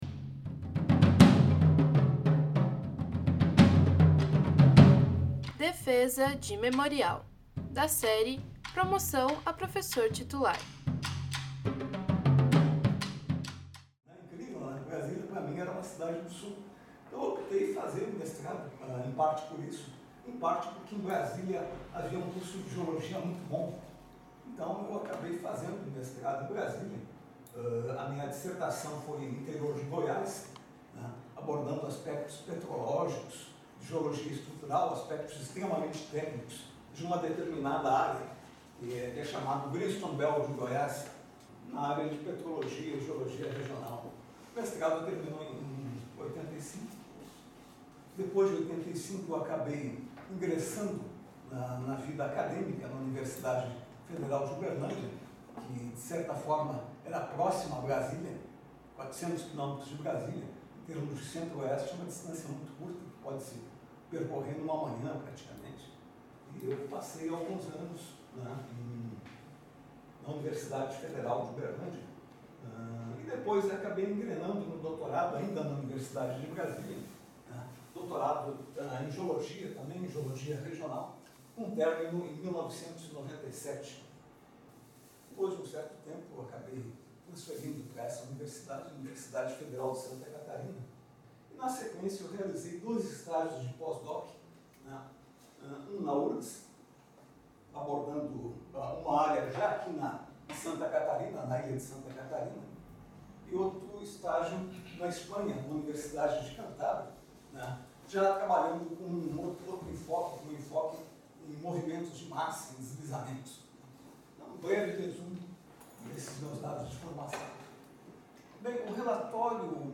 na sala 10/Usos Múltiplos do Departamento de História